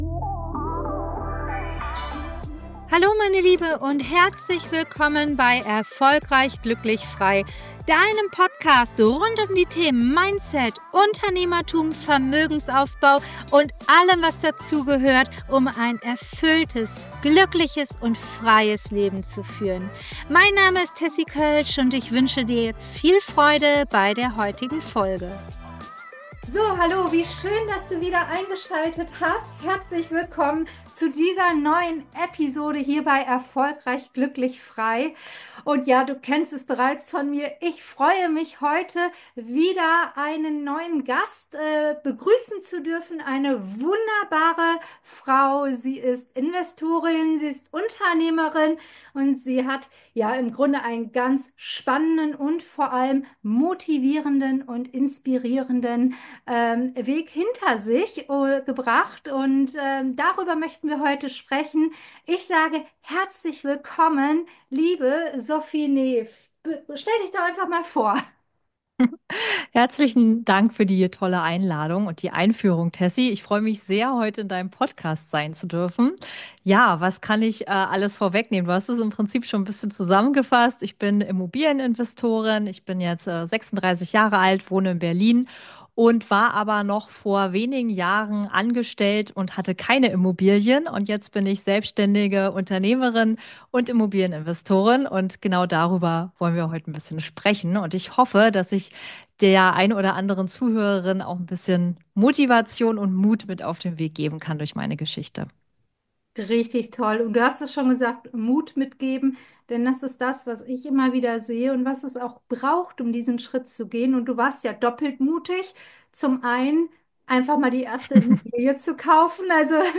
#17 Vom ersten Investment zum eigenen Unternehmen – Interview